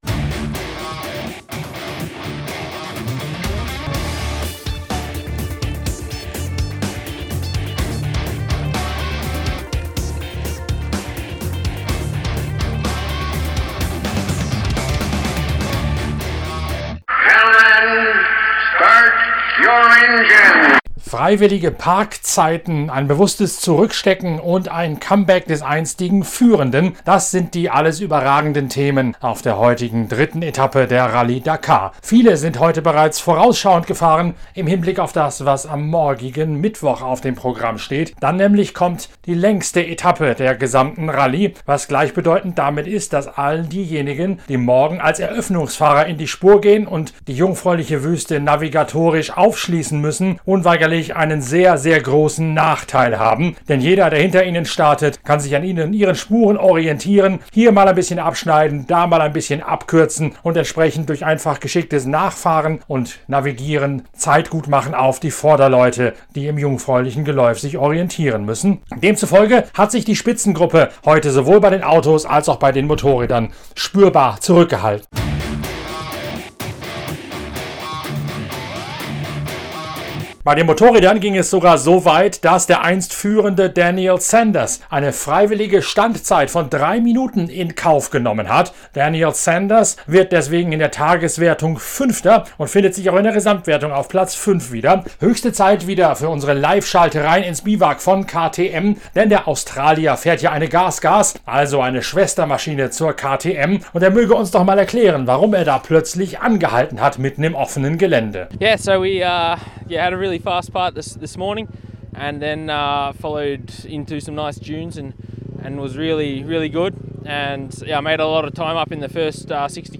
Bei den Motorrädern enthüllt eine Liveschalte ins Biwak von KTM und deren Schwestermarken Gas Gas sowie Husqvarna, dass es einige Fahrer taktisch haben angehen lassen – andere dagegen an navigatorischen Fallen beinahe gescheitert wären.
Das erklärt der Elsässer ebenso am Mikrofon dieses PITCAST wie Nasser Al-Attiyah, der Spitzenreiter, seinen strategisch geprägten Tag entblättert.